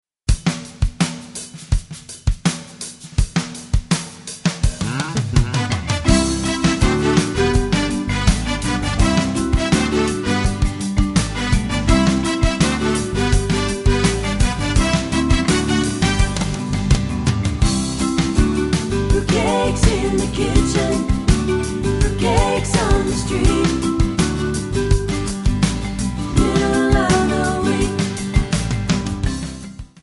Backing track Karaokes